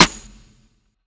Snares
DSG2_trp_rim_2.wav